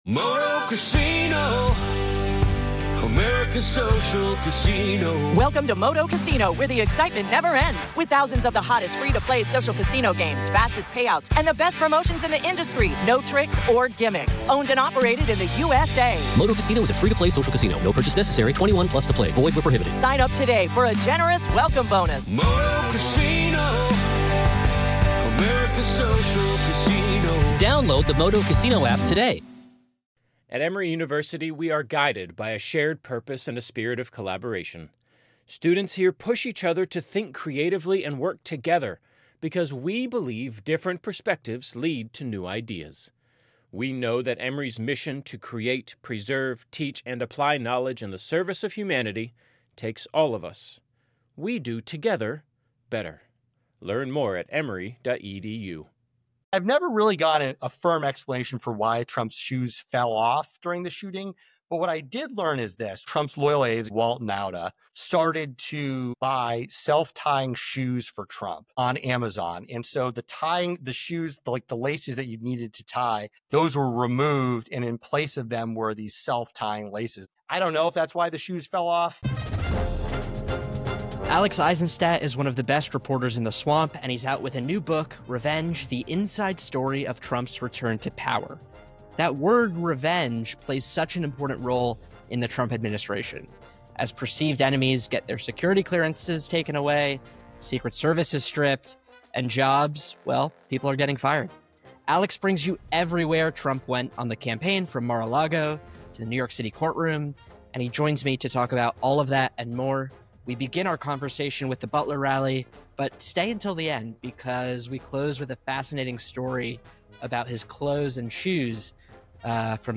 for a conversation about his book and previously unreported stories from Trump’s comeback campaign.